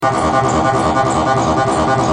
Sons Roland Tb303 -3
Basse tb303 - 53